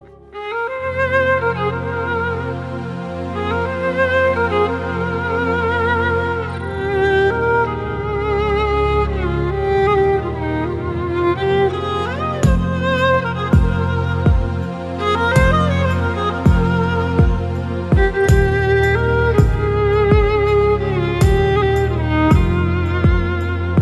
Category: Sad Ringtones